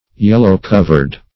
Search Result for " yellow-covered" : The Collaborative International Dictionary of English v.0.48: Yellow-covered \Yel"low-cov`ered\, a. Covered or bound in yellow paper.
yellow-covered.mp3